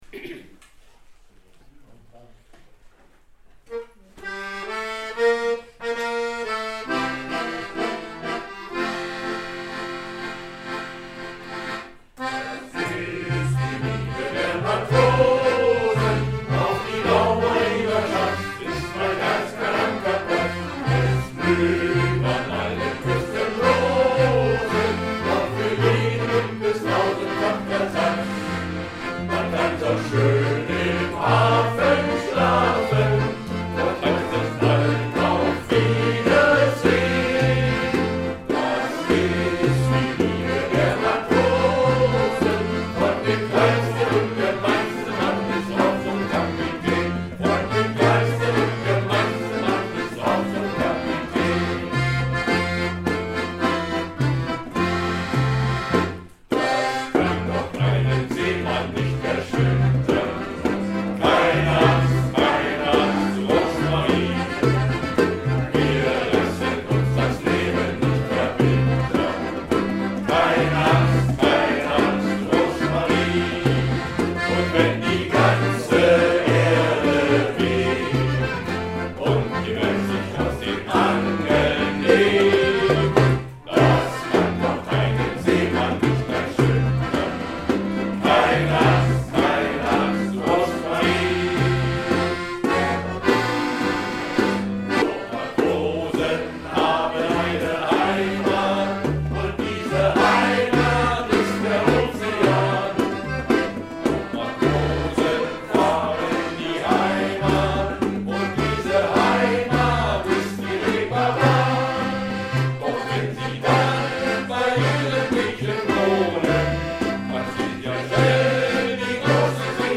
Shantys
Chor Chorgesang Jubiäum